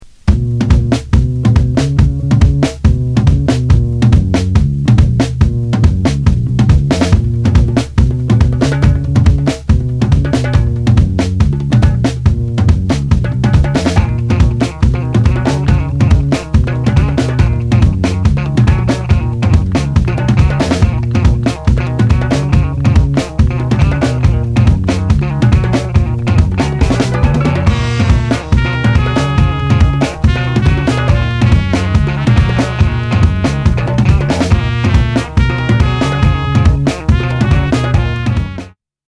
ソカ、ダンスホ−ルにも通じるバウンシー・リズムな